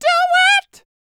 DD FALSET107.wav